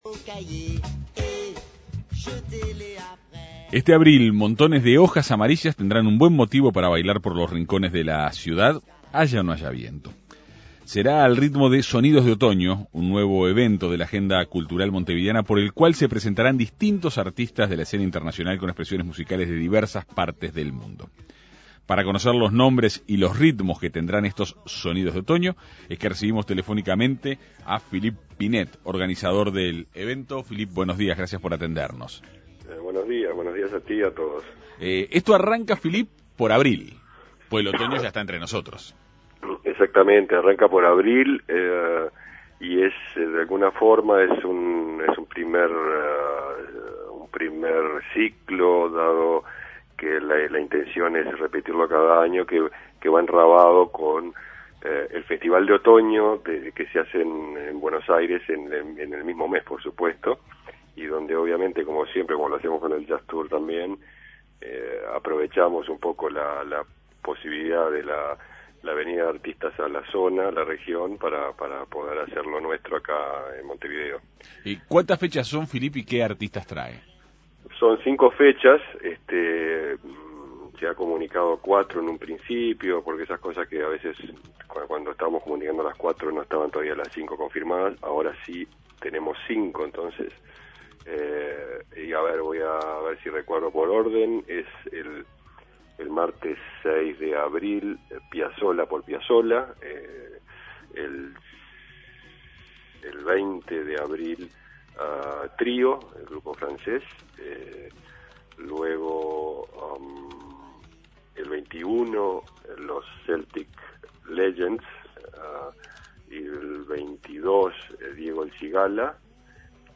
En Perspectiva Segunda Mañana dialogó